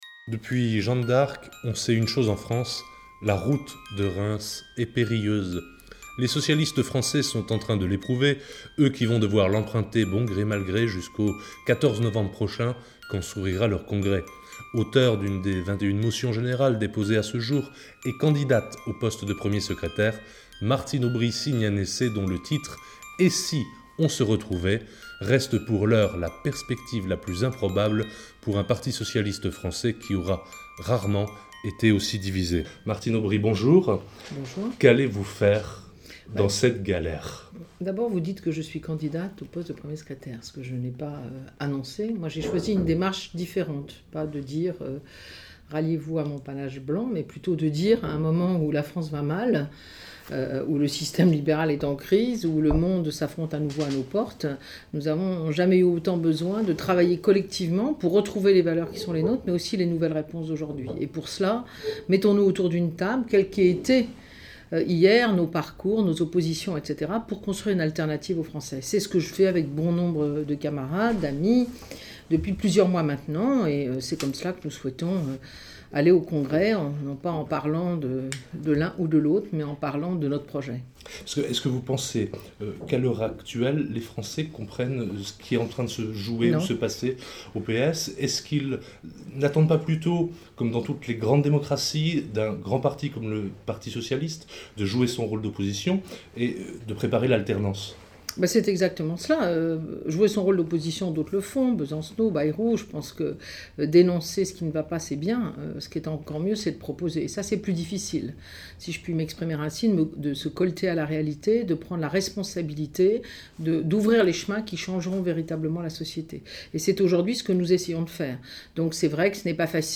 Entretien avec Martine Aubry
Entretien réalisé le 17 septembre, à Strasbourg, au Club de la Presse, en partenariat avec la Librairie Kléber.